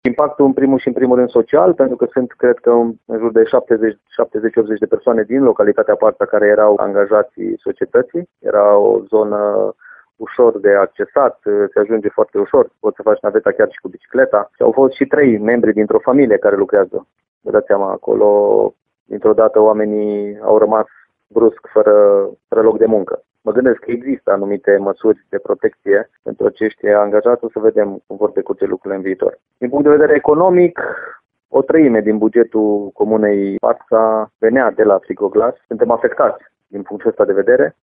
Potrivit primarului din localitate, Daniel Drăgan, impactul asupra comunei este unul major.
dragan-primar-parta.mp3